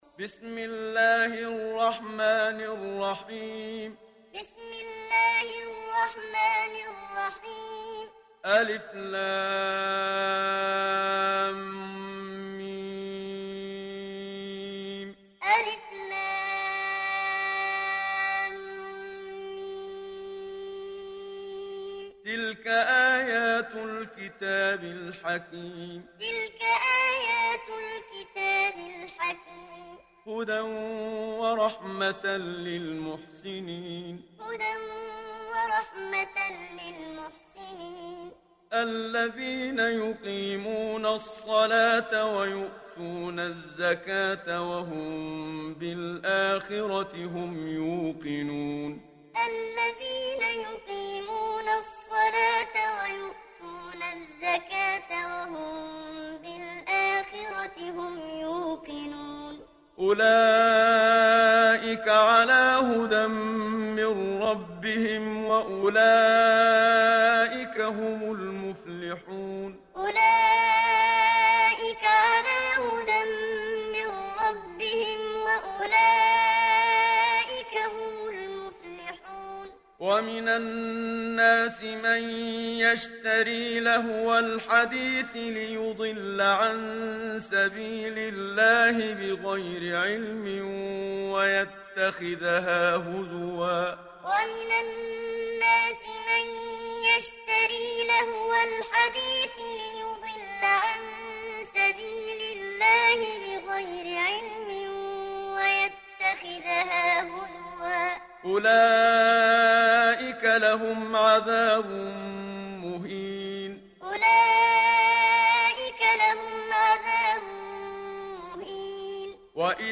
Surah Luqman Download mp3 Muhammad Siddiq Minshawi Muallim Riwayat Hafs from Asim, Download Quran and listen mp3 full direct links
Download Surah Luqman Muhammad Siddiq Minshawi Muallim